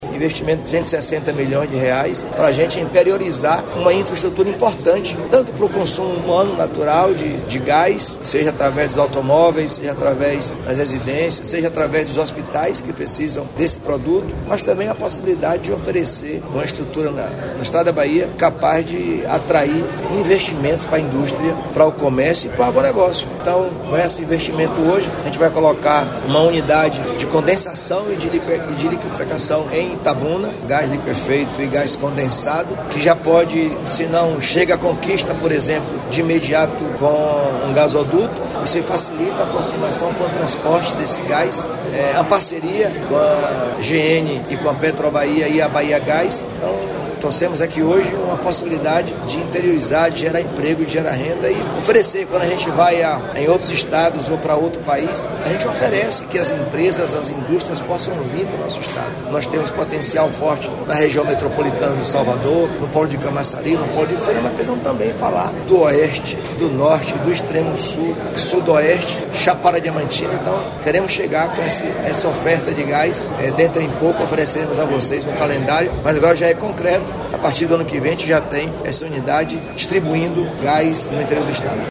🎤 Jerônimo Rodrigues, Governador da Bahia